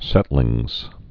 (sĕtlĭngz)